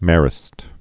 (mârĭst, măr-)